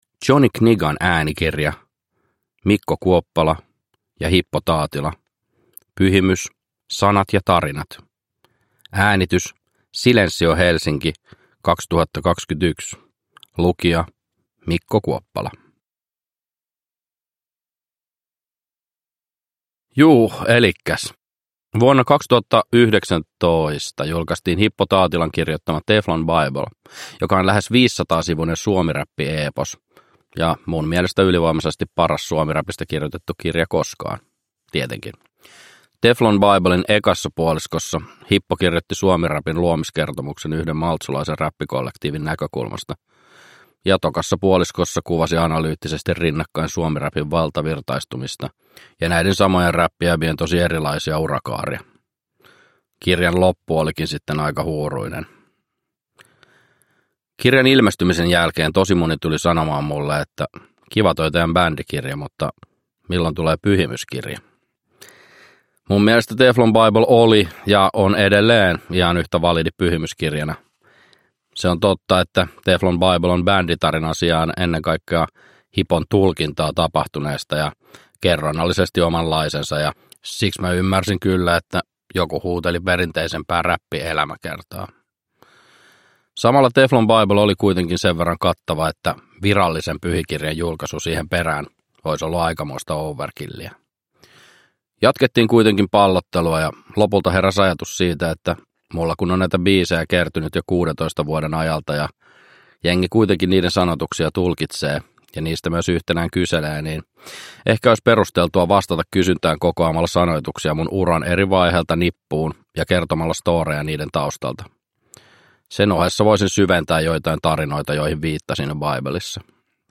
Pyhimys – Ljudbok – Laddas ner
Uppläsare: Mikko Kuoppala